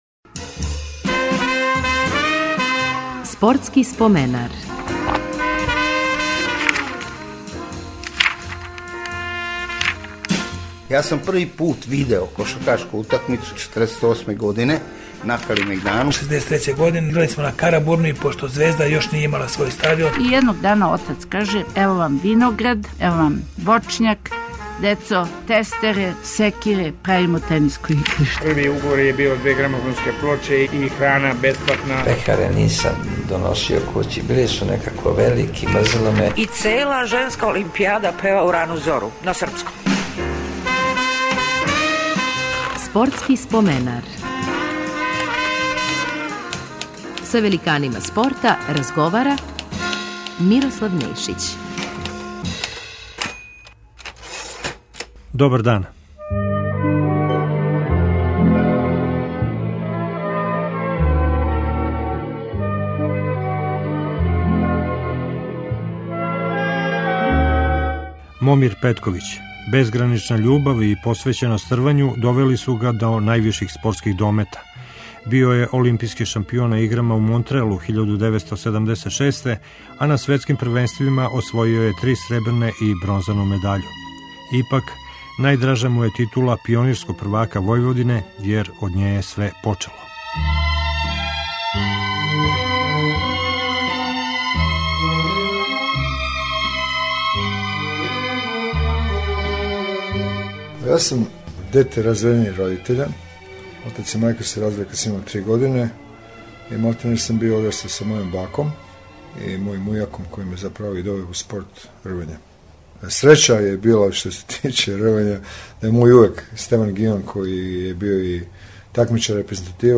Током лета ћемо репризирати неке од најзанимљивијих интервјуа, а од јесени уследиће серија нових разговора са великанима нашег спорта. Ове недеље бићете у прилици да поново чујете спортско-животну причу рвача Момира Петковића.